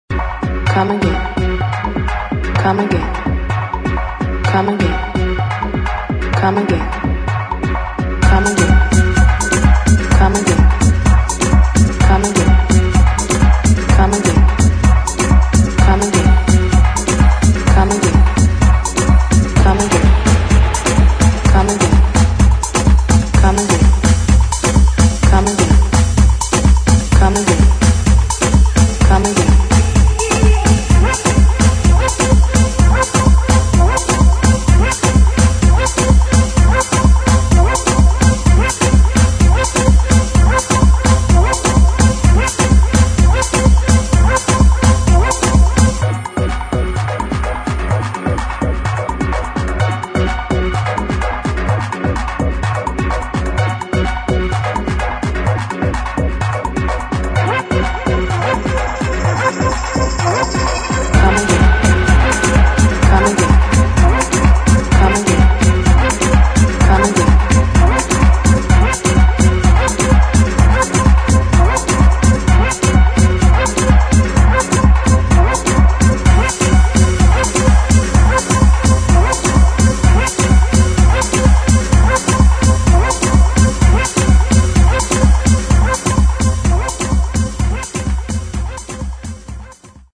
[ HOUSE | TECHNO ]